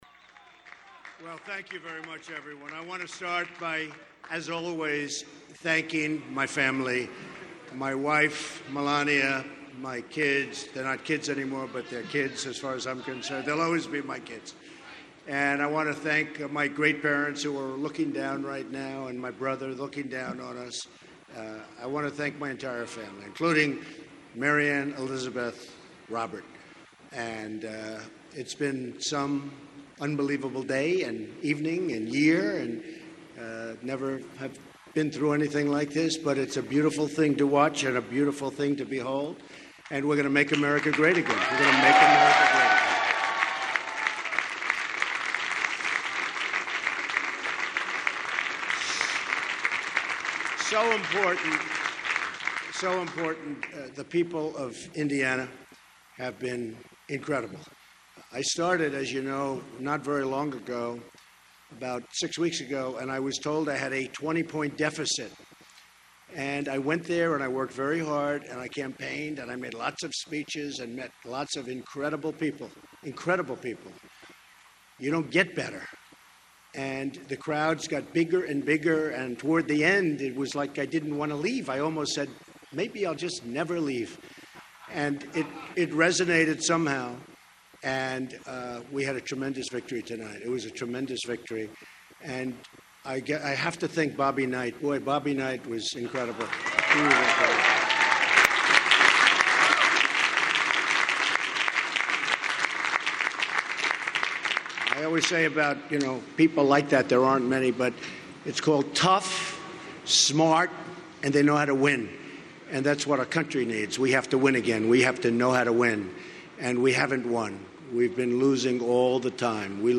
TrumpFullSpeech_01.mp3